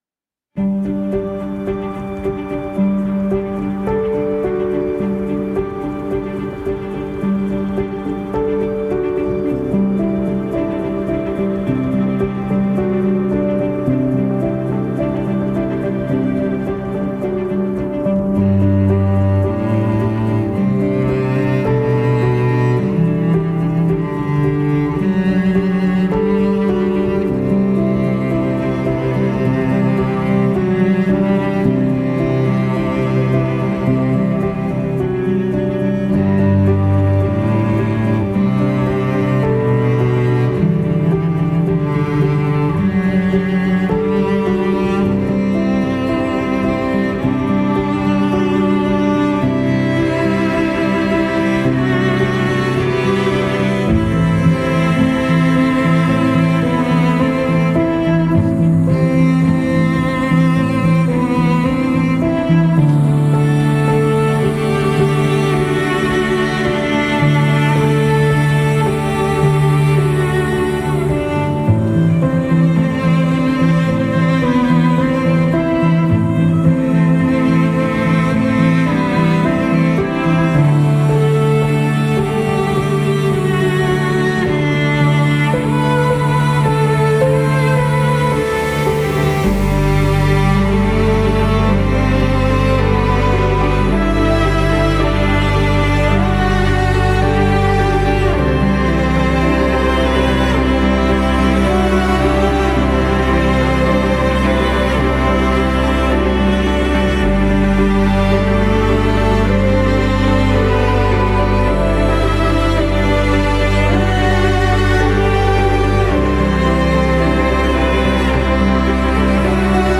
موسیقی بی کلام
امبینت